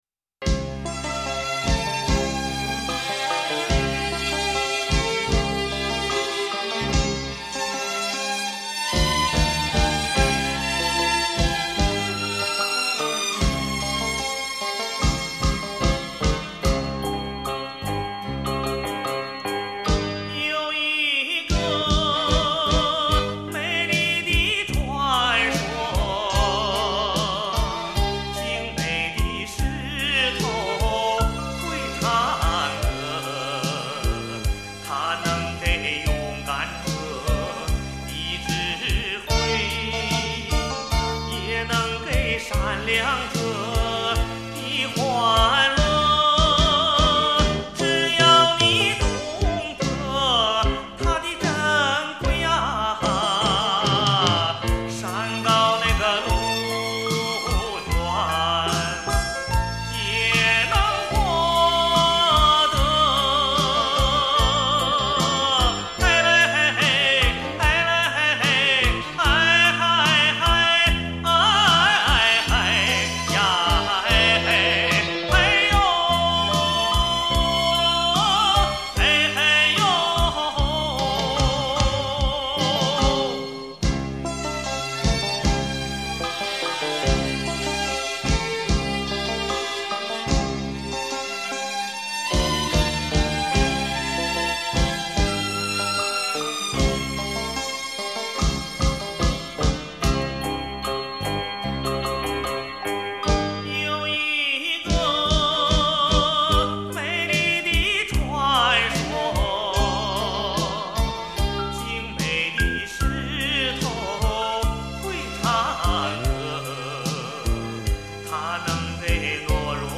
母带经美国太平洋微音公司HDCD二型处理器处理